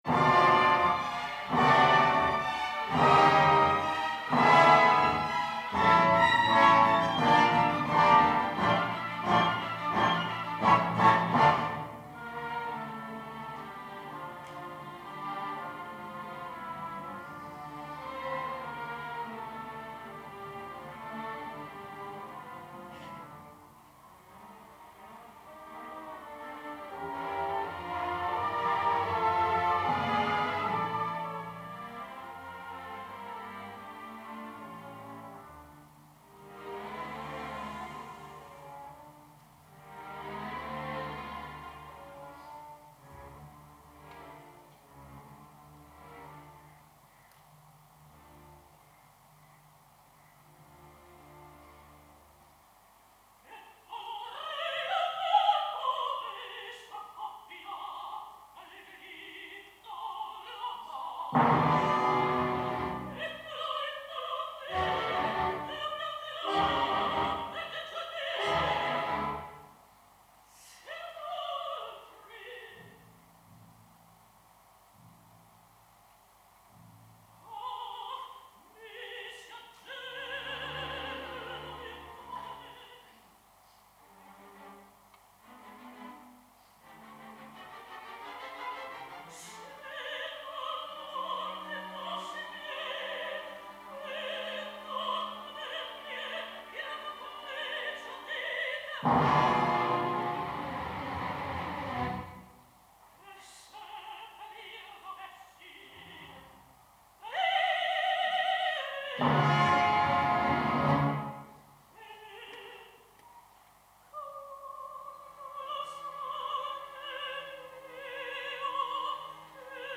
Dramatischer Sopran
Diese Hörproben sind Live-Mitschnitte durch Bühnenmikrophone, stellen also keine Studioqualität dar und sollen lediglich einen Stimm- und Interpretationseindruck vermitteln.